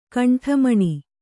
♪ kaṇṭhamaṇi